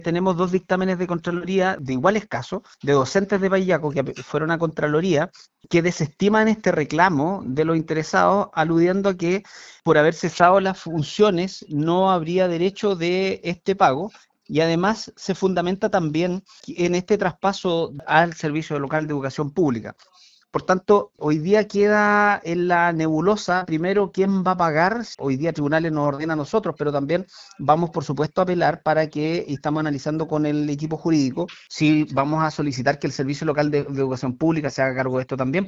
El alcalde de Paillaco, Cristian Navarrete, en conversación con Radio Bío Bío, junto con destacar la trayectoria de las exdocentes, informó que mantienen sus argumentos y que analizan con el equipo jurídico cuáles van a ser las acciones a seguir respecto a este fallo.